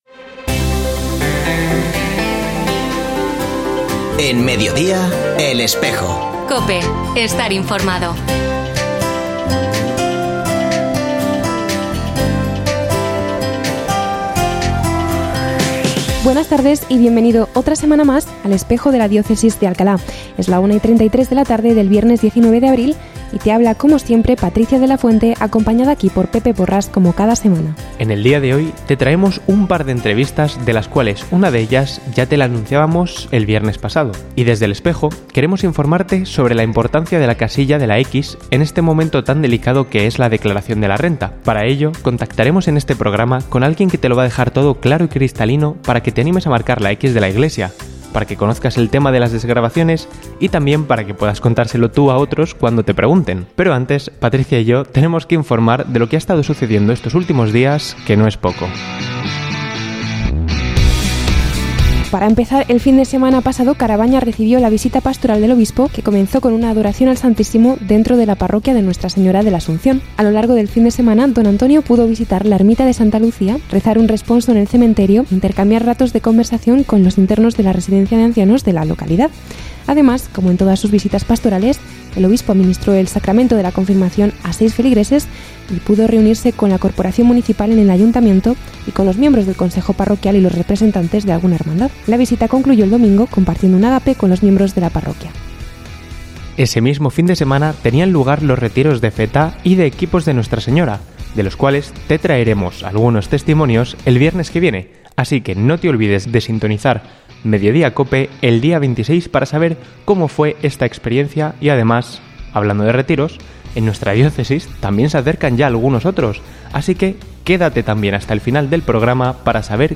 Ofrecemos el audio del programa de El Espejo de la Diócesis de Alcalá emitido hoy, 19 de abril de 2024, en radio COPE. Este espacio de información religiosa de nuestra diócesis puede escucharse en la frecuencia 92.0 FM, todos los viernes de 13.33 a 14 horas.